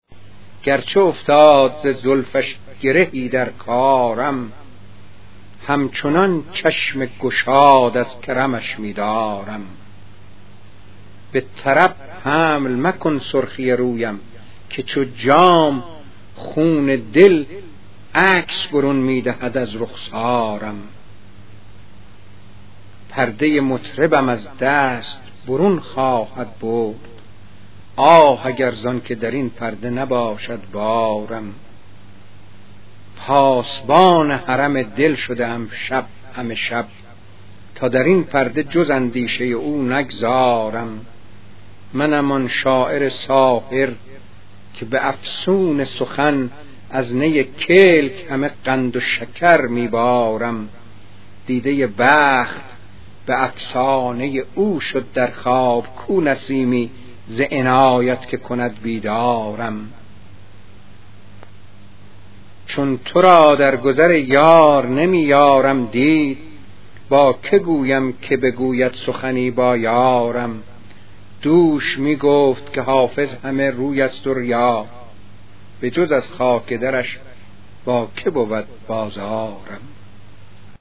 🎵 پخش صوتی غزل با صدای موسوی گرمارودی: